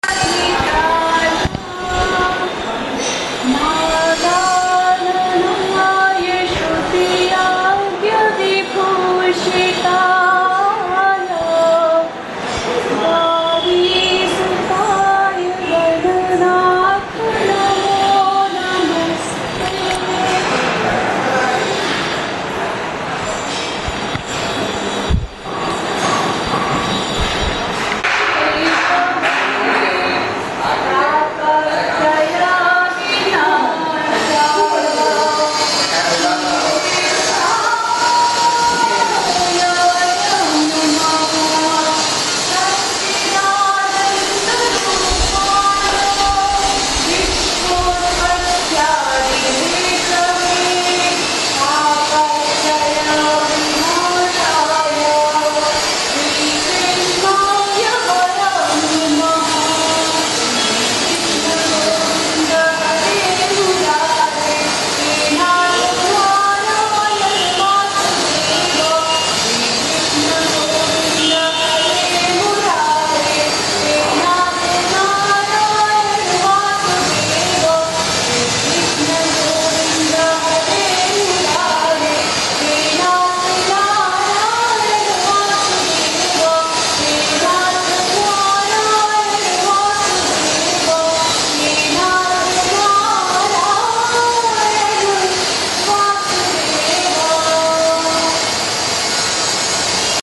這是一段我在印度瓦拉納西印度教神廟中錄製的聲音，出自一個普通的來拜神的女人，這是段頌詩，當時驚為天籟，雖然背景有電鋸的聲音，但依然可以聽清並且感受到當時的氣場。